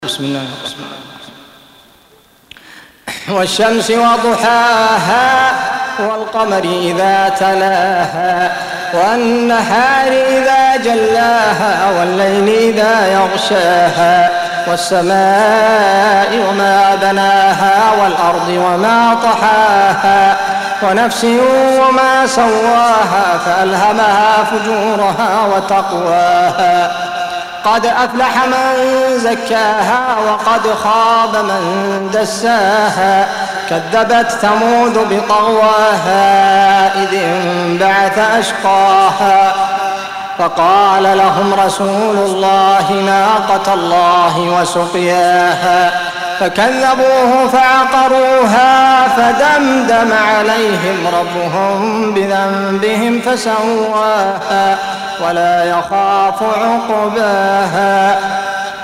Surah Sequence تتابع السورة Download Surah حمّل السورة Reciting Murattalah Audio for 91. Surah Ash-Shams سورة الشمس N.B *Surah Includes Al-Basmalah Reciters Sequents تتابع التلاوات Reciters Repeats تكرار التلاوات